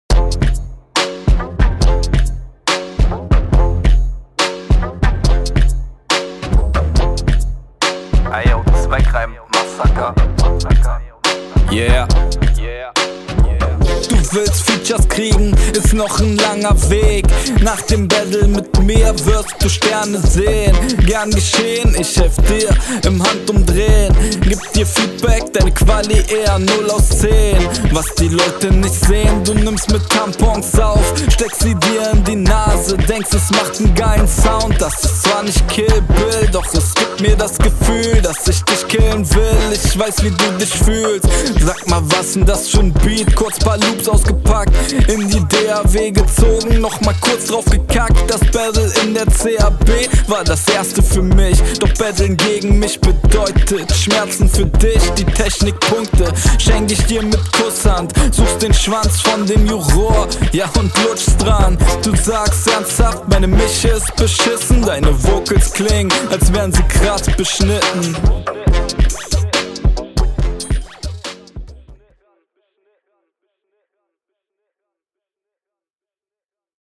Rap ist passabel, aber die Aussprache ist undeutlicher als in der Hinrunde …